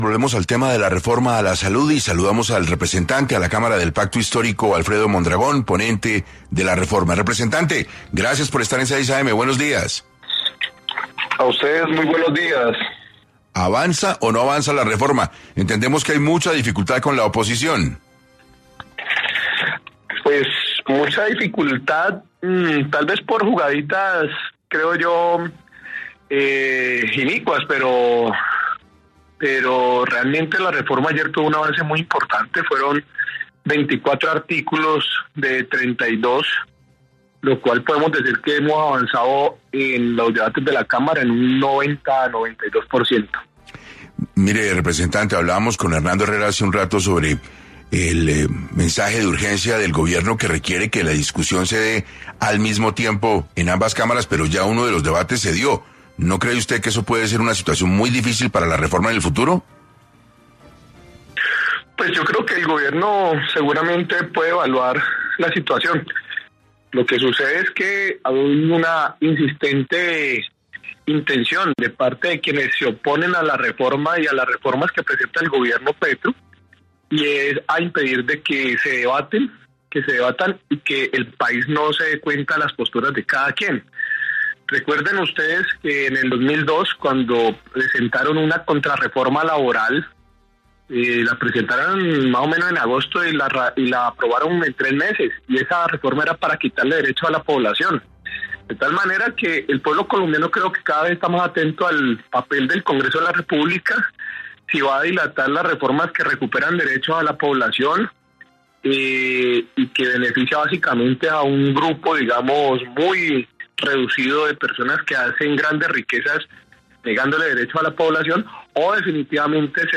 Alfredo Mondragón, representante a la Cámara, estuvo en 6AM para hablar de todo lo referente a la Reforma a la Salud.
En este orden de ideas, el representante Alfredo Mondragón, que además es ponente de esta reforma, estuvo tras los micrófonos de 6AM, para profundizar en todo lo referente a este debate que se libra por estos días en el Congreso.